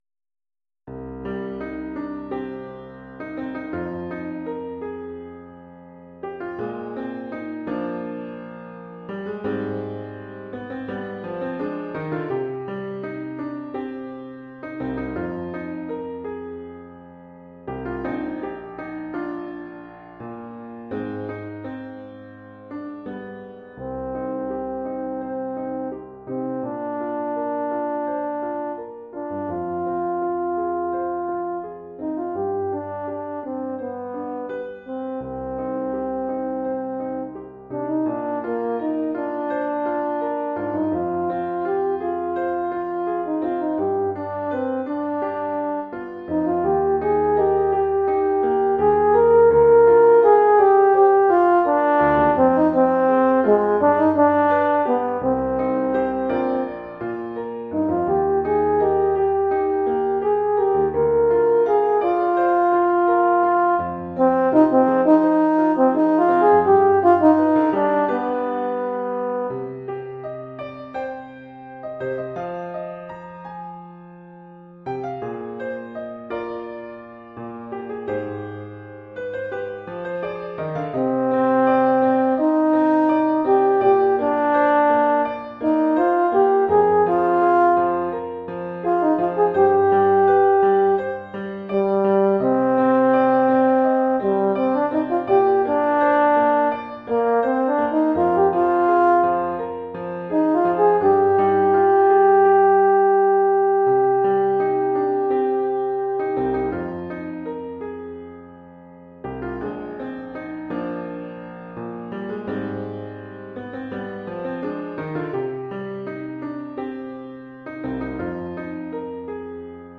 Cor d'harmonie
Oeuvre pour cor d’harmonie et piano.